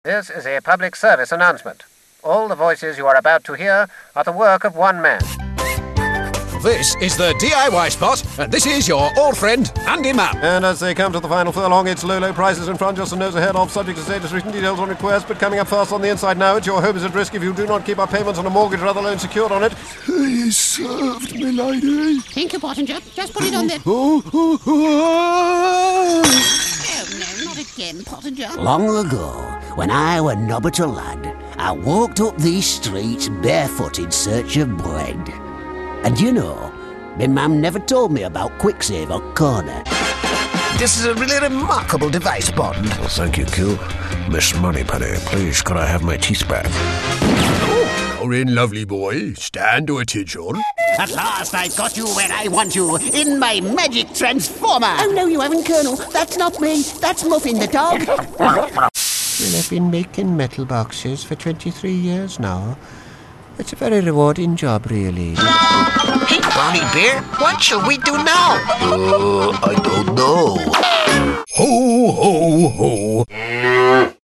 Englisch (Britisch)
Animation
Meine Stimme wird normalerweise als warm, natürlich und unverwechselbar beschrieben und wird oft verwendet, um Werbe- und Erzählprojekten Klasse und Raffinesse zu verleihen.
Neumann U87-Mikrofon